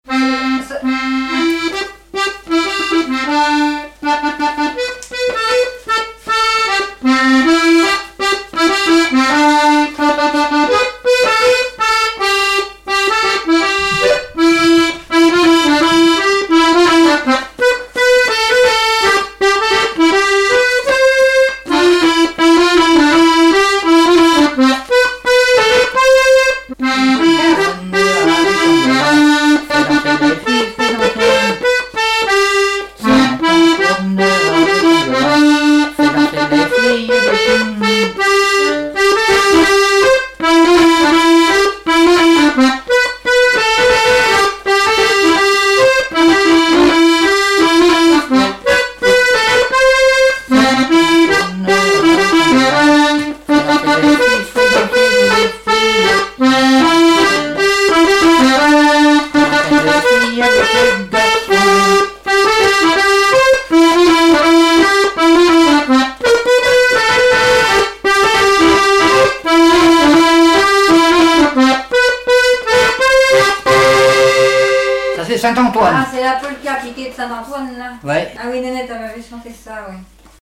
danse : polka
Répertoire du musicien sur accordéon chromatique
Pièce musicale inédite